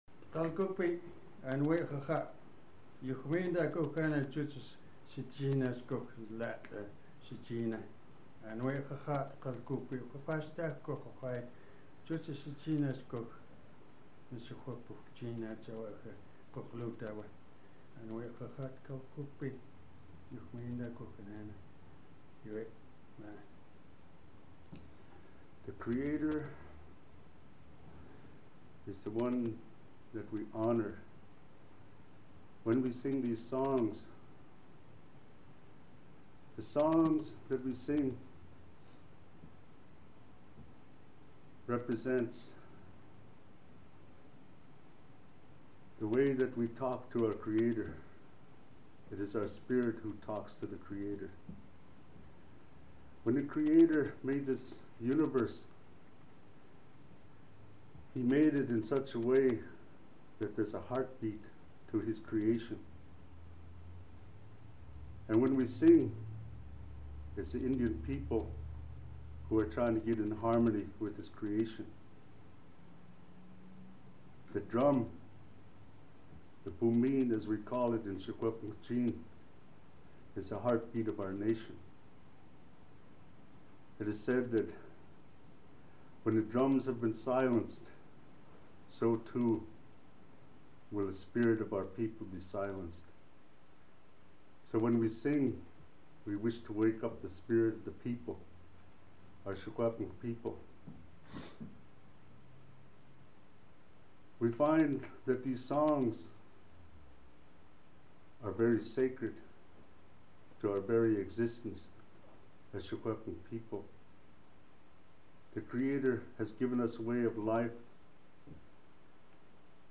Drum songs [sound recording]
Produced at Perry's Recording Studio, Kamloops, B.C.,